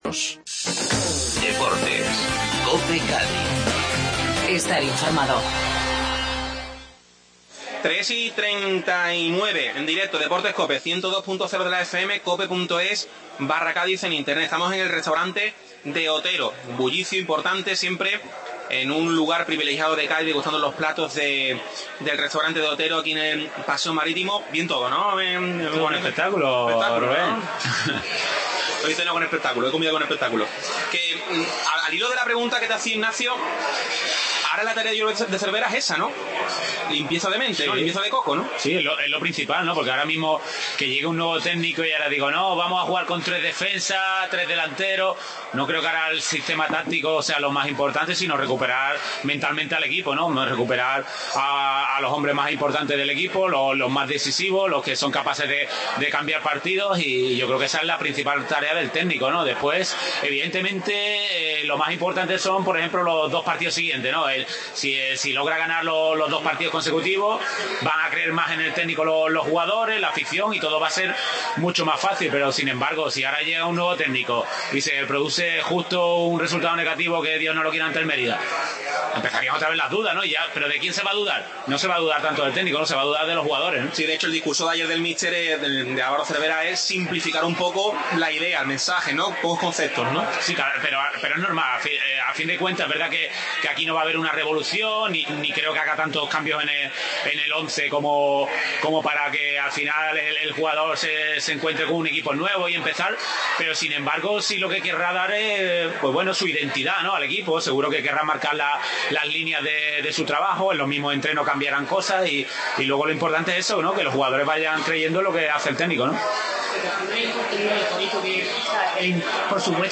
Segunda parte de la tertulia